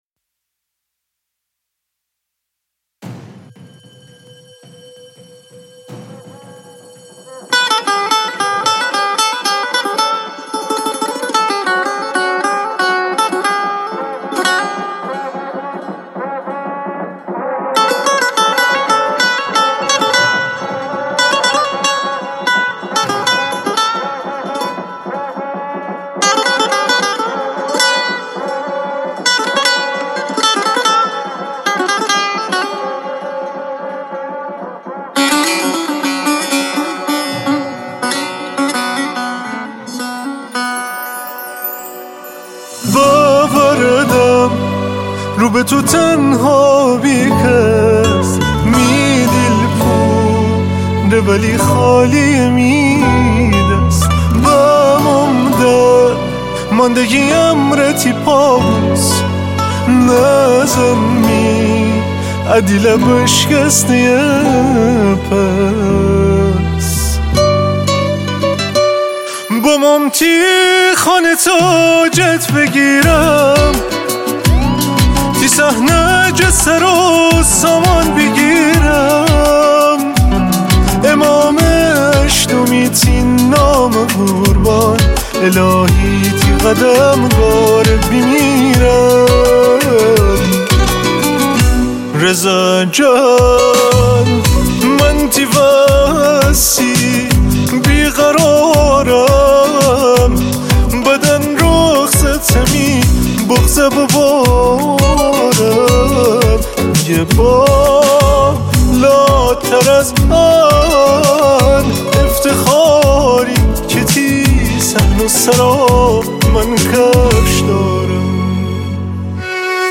مذهبی و نوحه